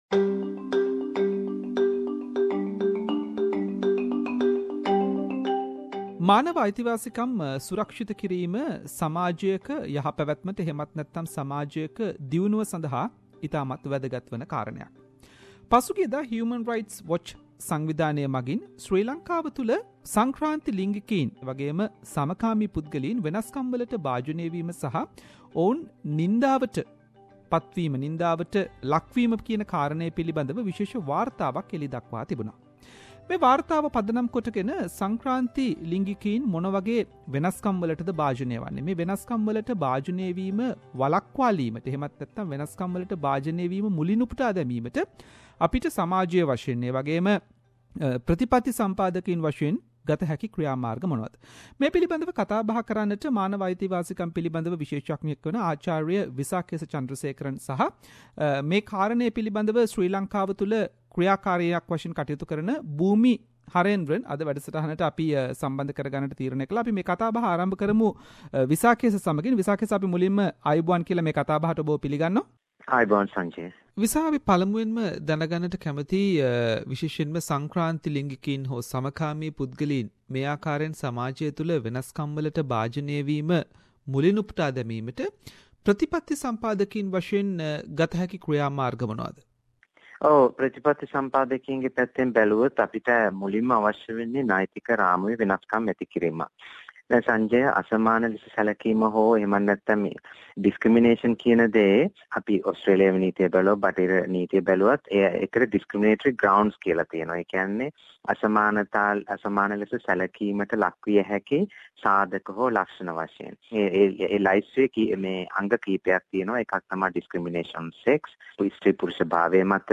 Recently Human rights watch released a report regarding challenges faced by transgender community in Sri Lanka. SBS Sinhalese program interviewed
Human rights activist based in Australia
Transgeder activist from Sri Lanka